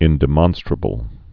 (ĭndĭ-mŏnstrə-bəl)